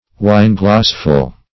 wineglassful - definition of wineglassful - synonyms, pronunciation, spelling from Free Dictionary
Search Result for " wineglassful" : The Collaborative International Dictionary of English v.0.48: Wineglassful \Wine"glass`ful\;, n. pl.